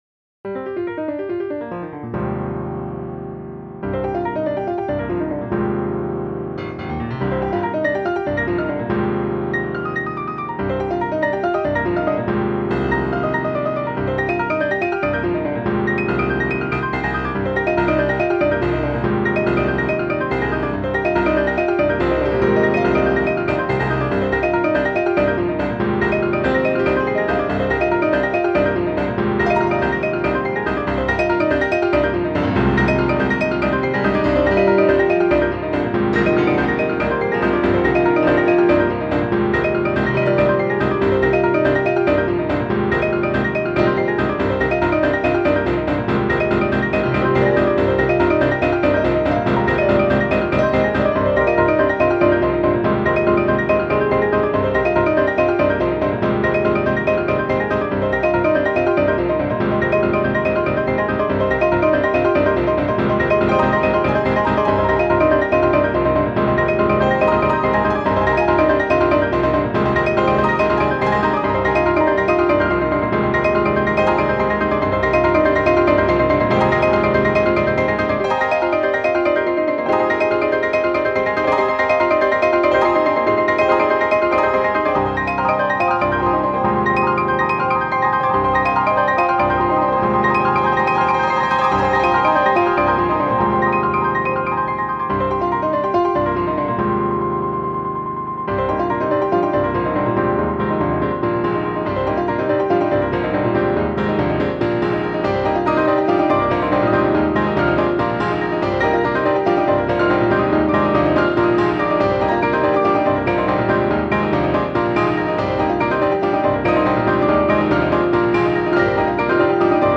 Piano x Piano x Piano x Piano x Piano x Piano...